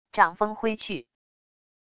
掌风挥去wav音频